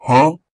combobreak.ogg